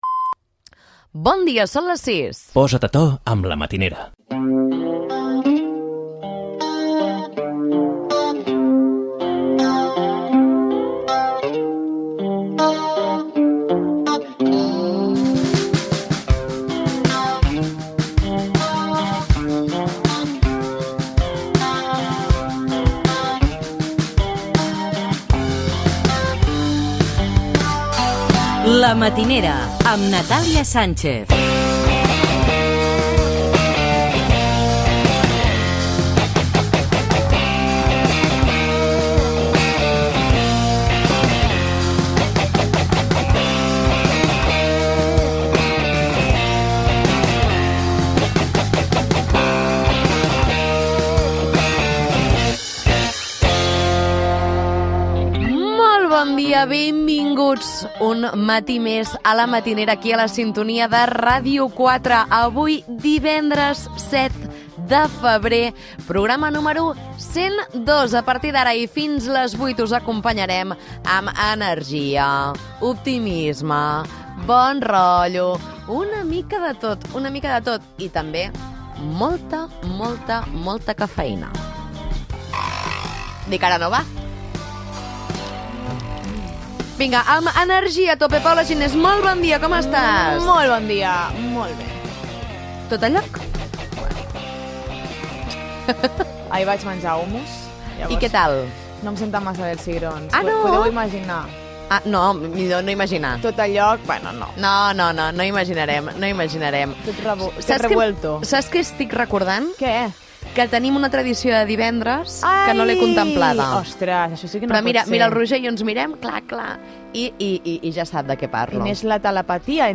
Hora, careta, data, presentació i diàleg amb l'equip
Gènere radiofònic Entreteniment